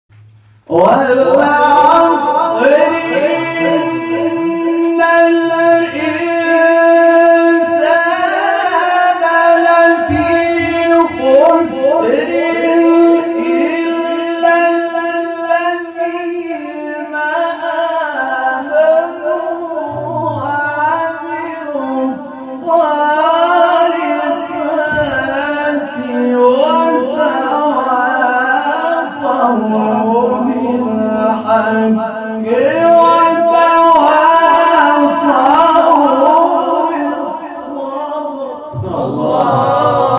شبکه اجتماعی: فراز‌هایی صوتی از تلاوت قاریان ممتاز کشور ارائه می‌شود.